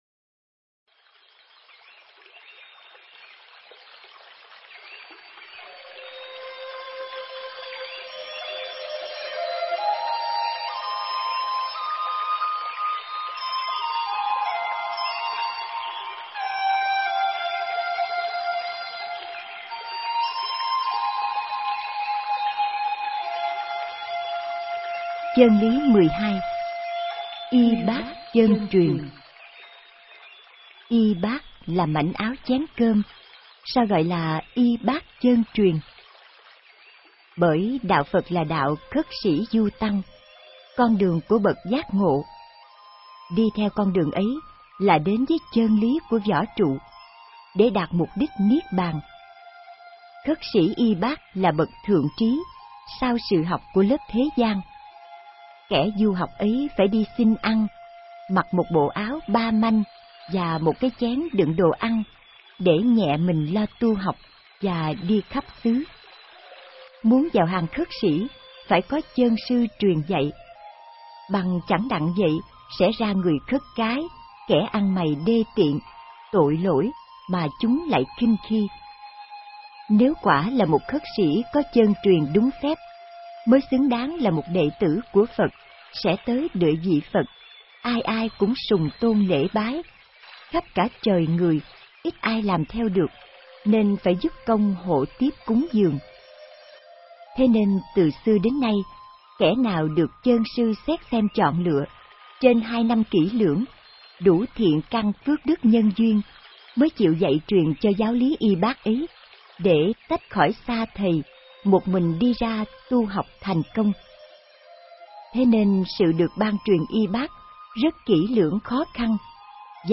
Nghe sách nói chương 12.